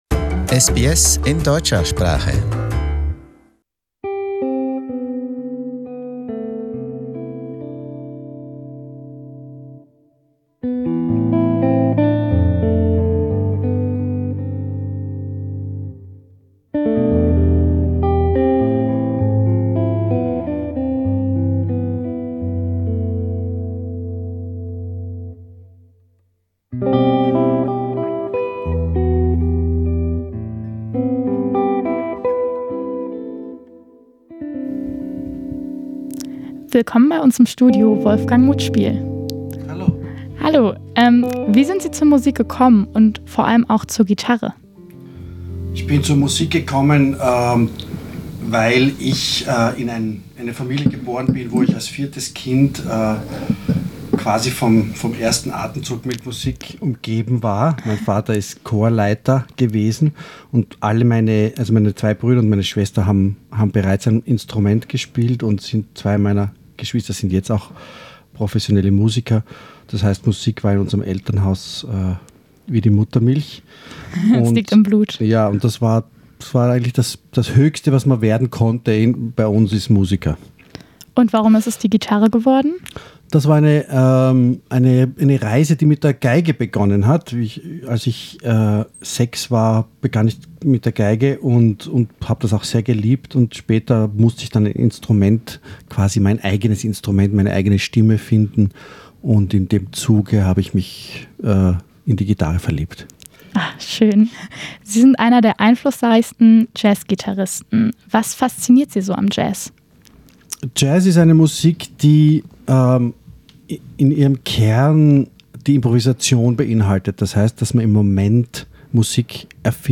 Passion and purpose: An interview with Austrian jazz guitarist Wolfgang Muthspiel in Australia
Der österreichische Jazzgitarrist Wolfgang Muthspiel im SBS Studio am Federation Square in Melbourne Source: SBS